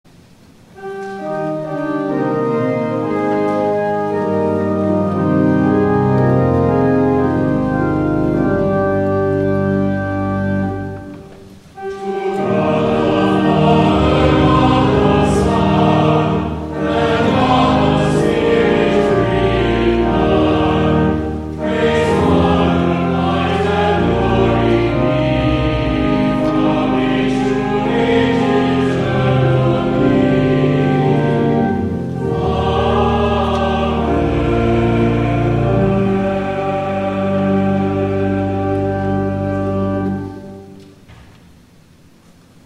FIRST SUNDAY OF ADVENT
*THE CHORAL RESPONSE